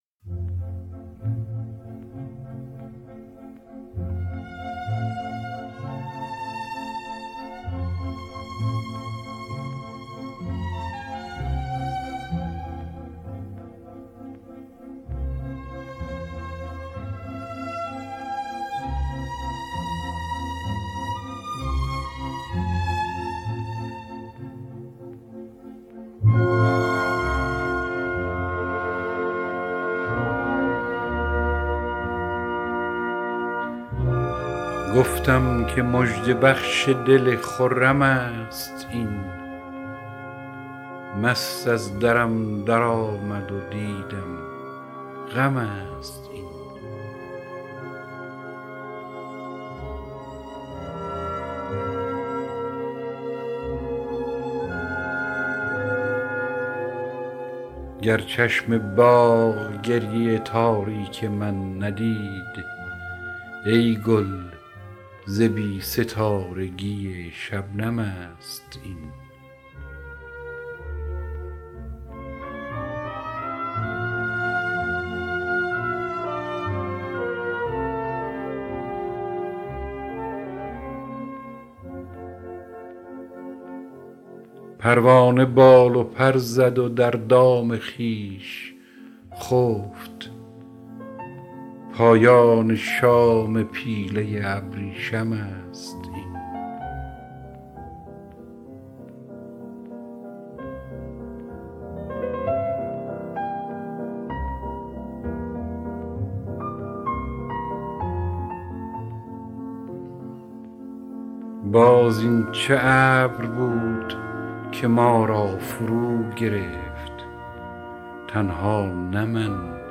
دانلود دکلمه چندین هزار امید بنی آدم با صدای هوشنگ ابتهاج
گوینده :   [هوشنگ ابتهاج]